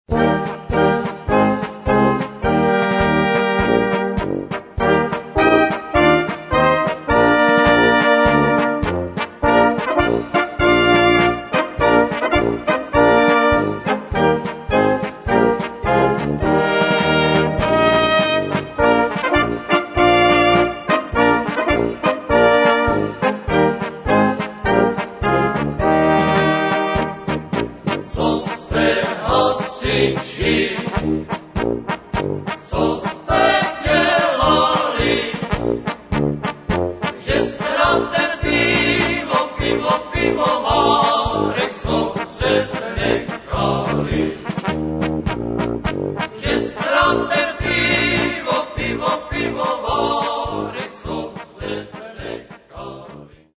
polka 3:37-upr.